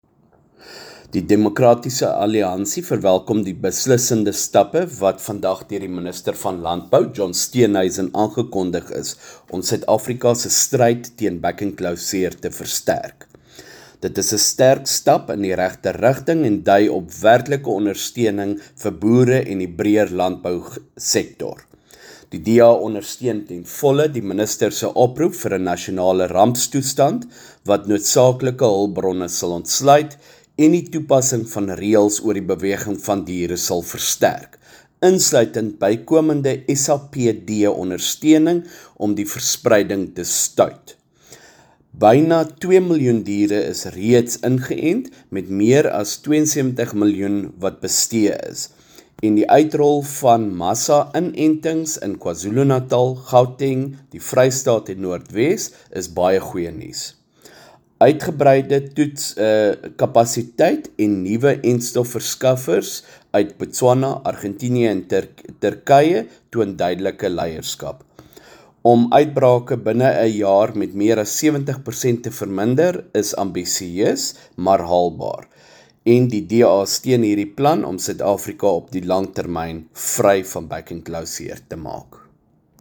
Afrikaans and English soundbites by Beyers Smit MP.